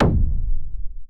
EXPLOSION_Medium_Implosion_stereo.wav